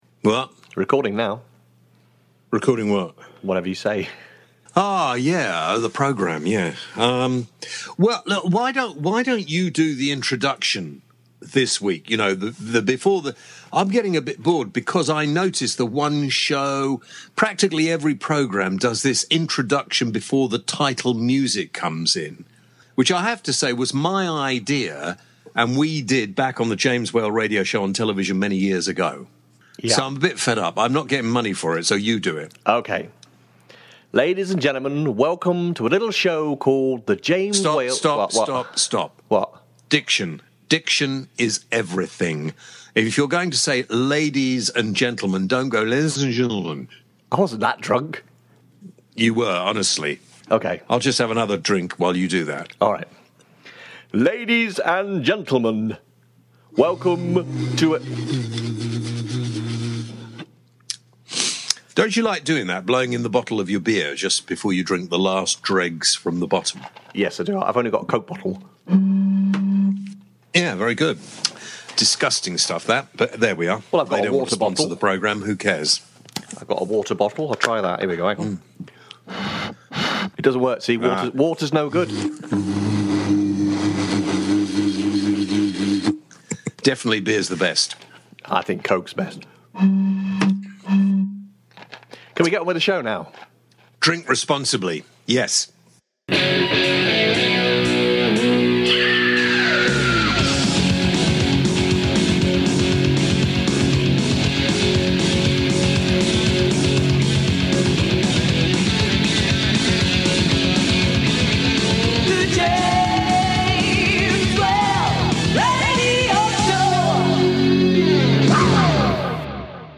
Mike Nolan – Bucks Fizz This weeks show James chats to Mike Nolan from the original Bucks Fizz and winner of Eurovision, Also on the show. Bear awareness week, is Nigel Farage the best man for the job? Should we bring back national service?